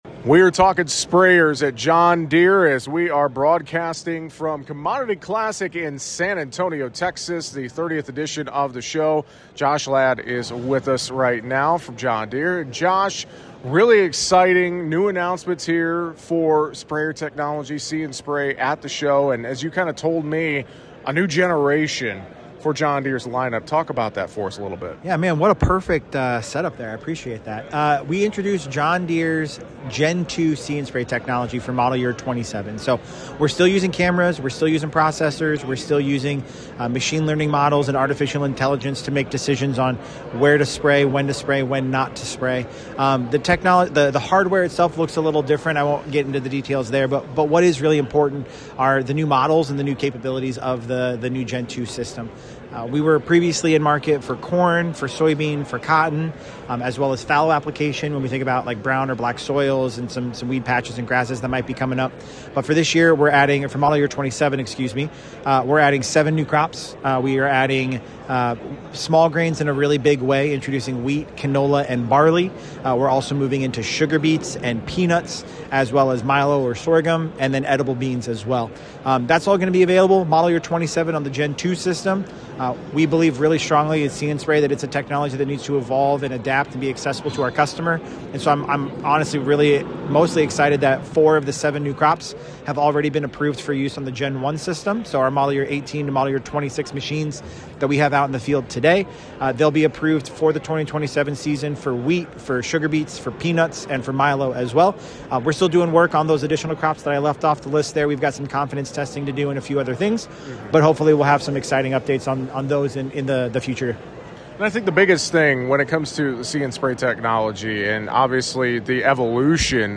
During the 2026 Commodity Classic in San Antonio, TX, we got to see some of this technology on the trade show floor